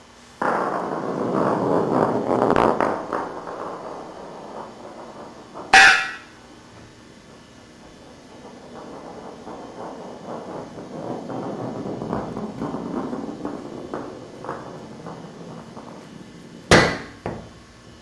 ball bonk clang floor metal rolling thud sound effect free sound royalty free Memes